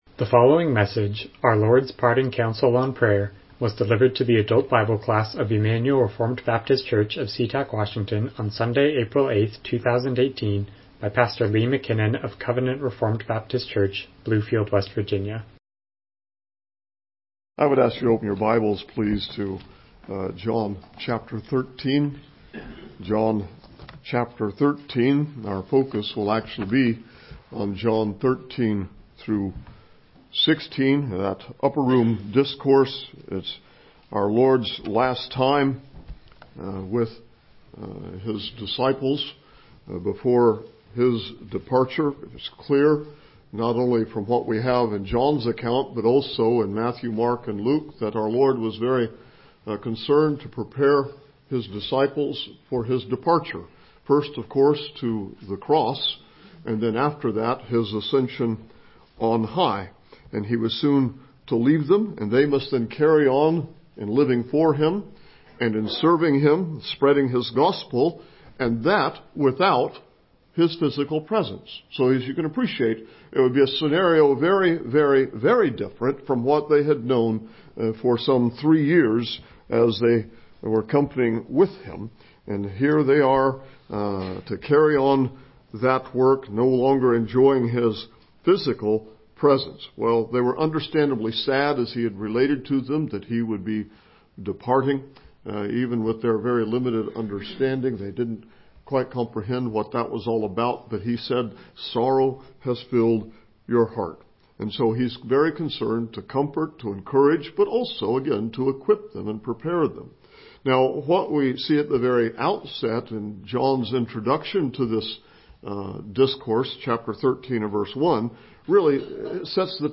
Miscellaneous Service Type: Sunday School « Christ Is Risen